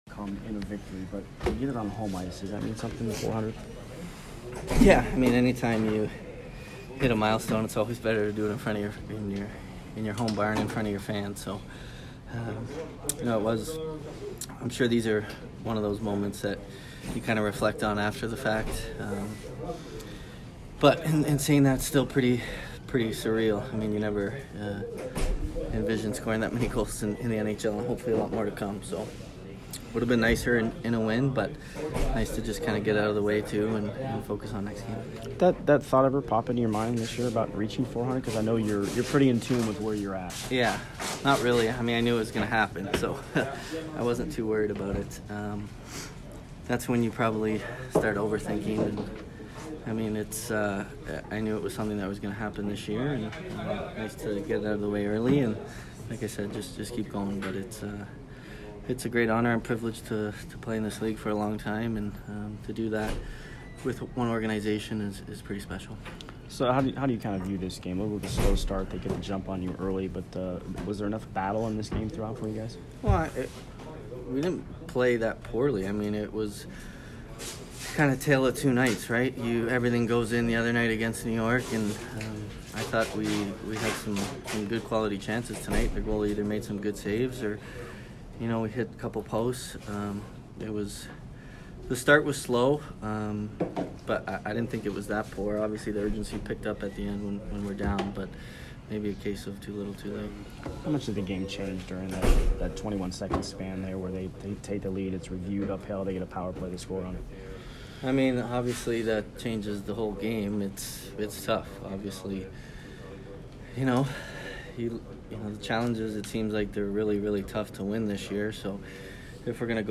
Steven Stamkos post-game 11/16